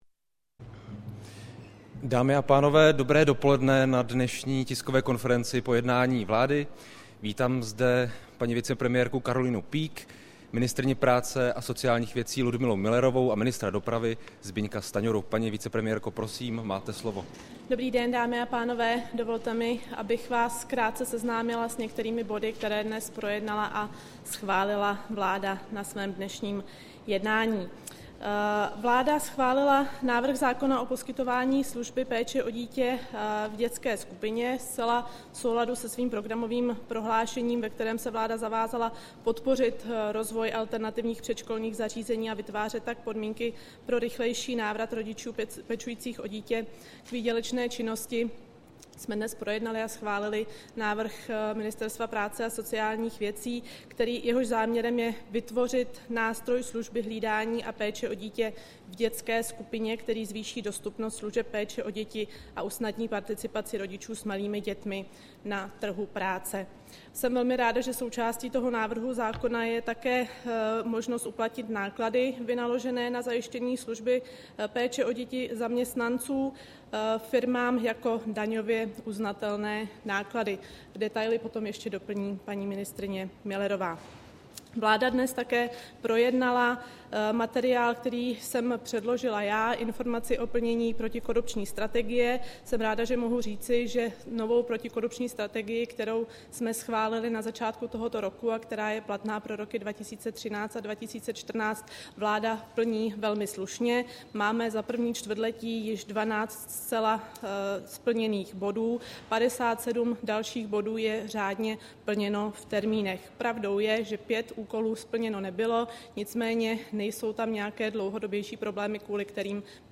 Tisková konference po jednání vlády, 22. května 2013